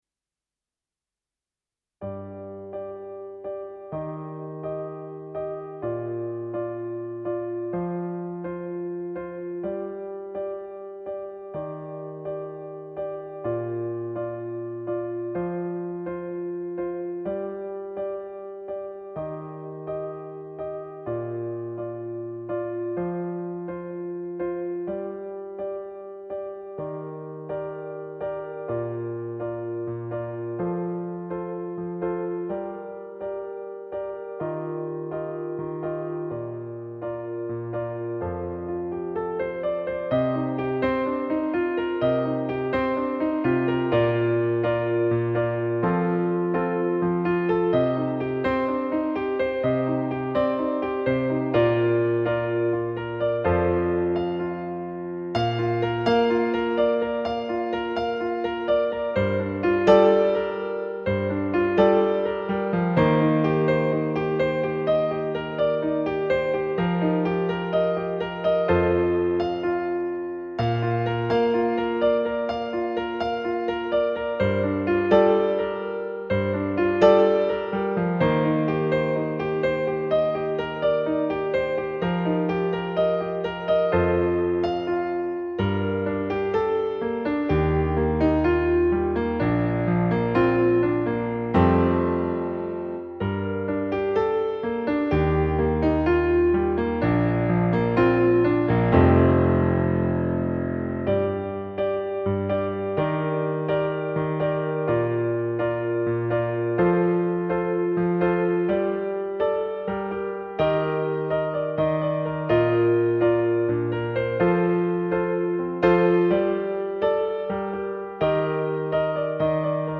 All-Of-Me-Backing.mp3